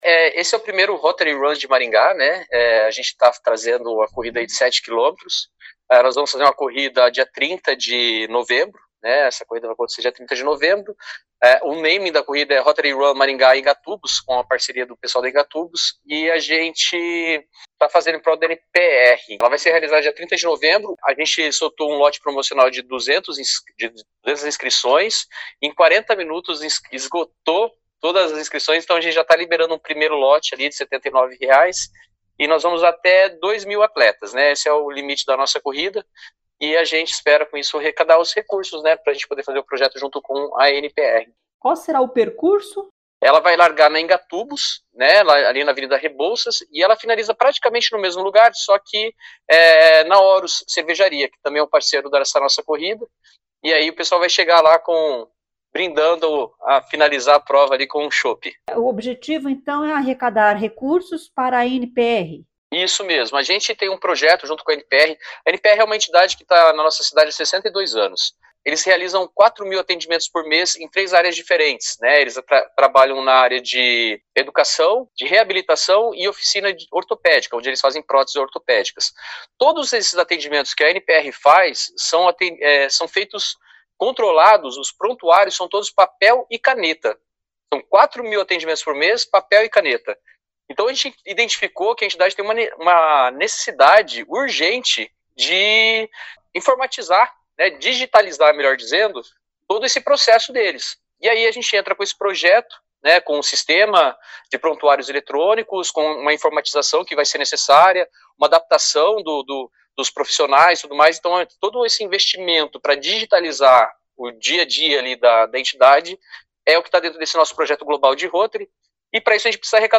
fala sobre a corrida.